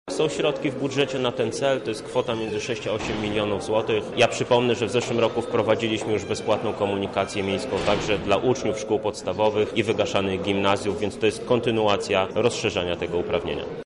– mówi Michał Krawczyk, radny KWW Krzysztof Żuk.